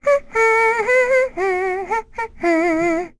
Rehartna-Vox_Hum.wav